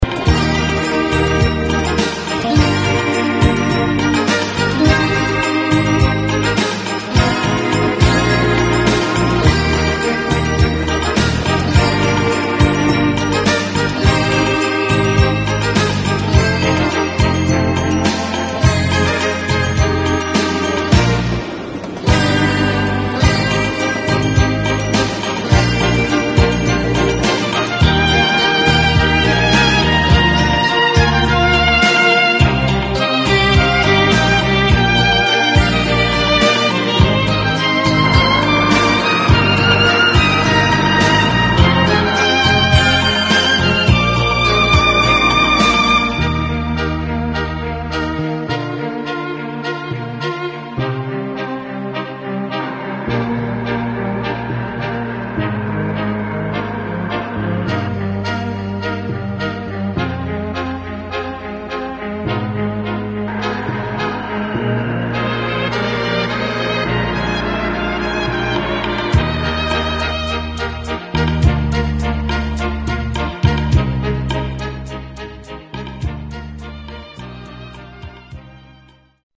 Помогите пожалуйчта опознать инструменталы